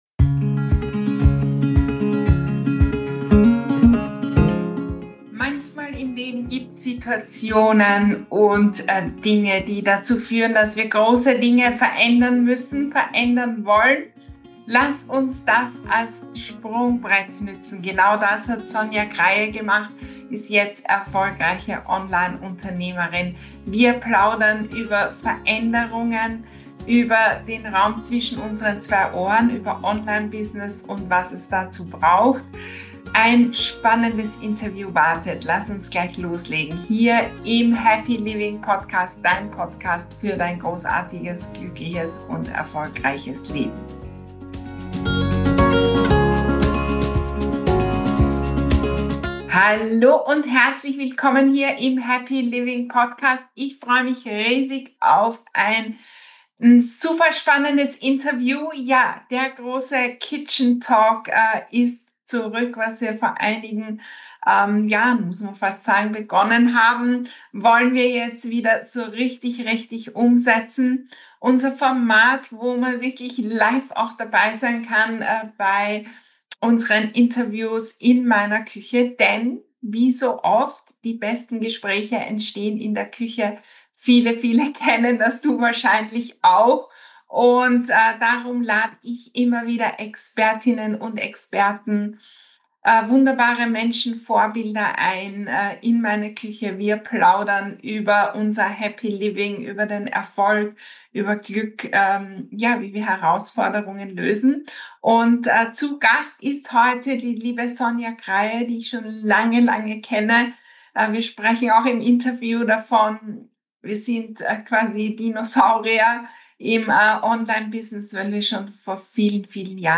Wir plaudern über Veränderungen, den Raum zwischen unseren zwei Ohren und Online-Business. Dieses spannende Happy Living Podcast-Interview ist für alle, die neu mit etwas starten oder Dinge auf den nächsten Level bringen wollen.